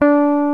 FLYING V 2.wav